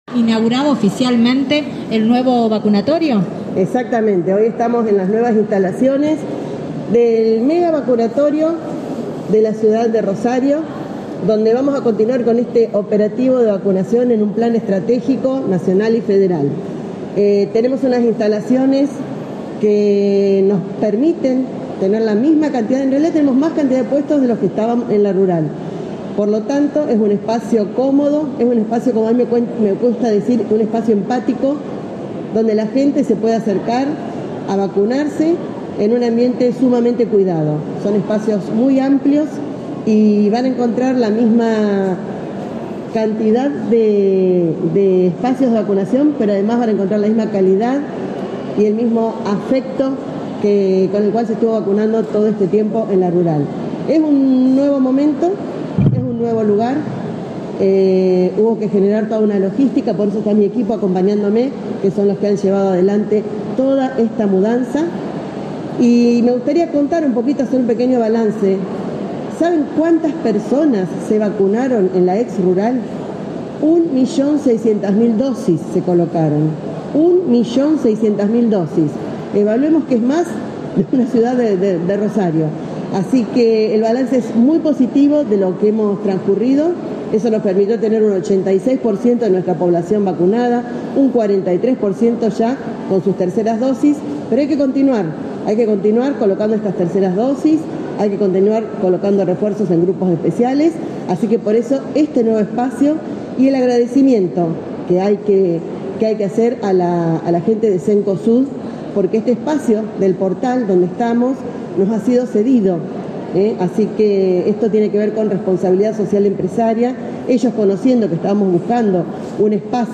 Sonia Martorano, ministra de Salud brindó una conferencia de prensa donde dio detalles de estas nuevas instalaciones y el avance de la vacunación.